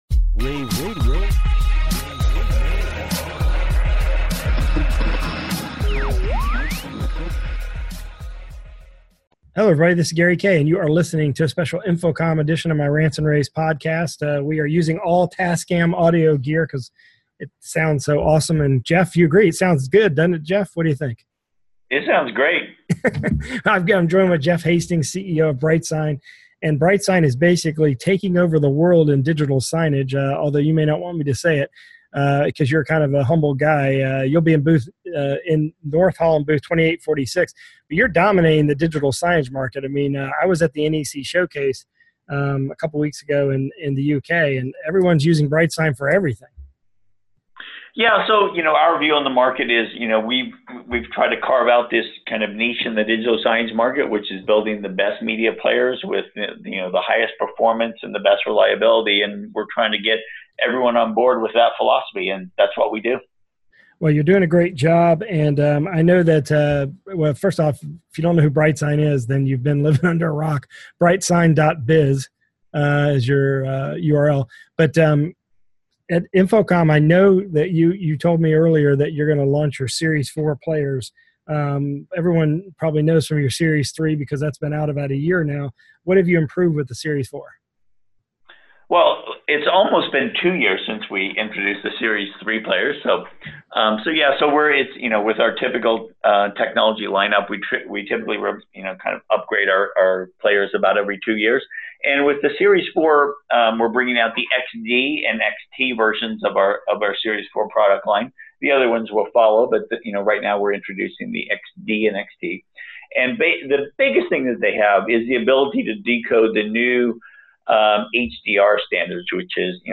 InfoComm Radio